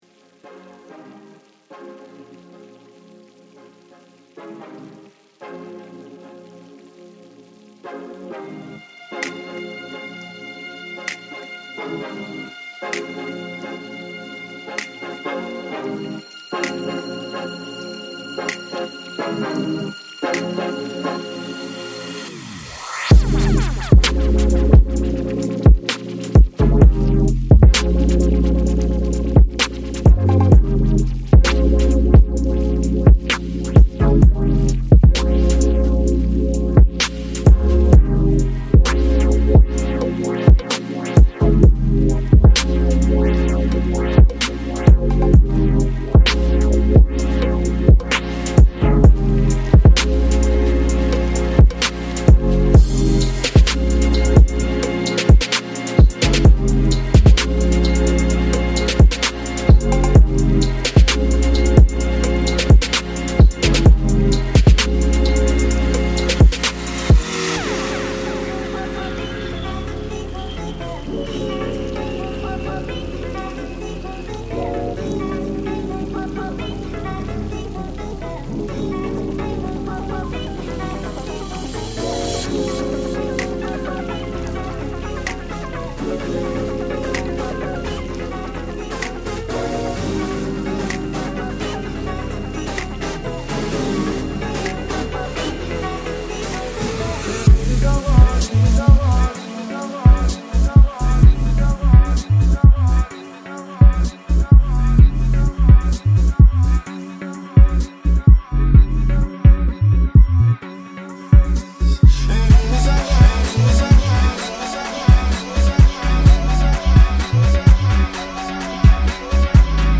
电音观星趴